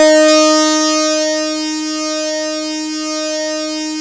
OSCAR D#5 2.wav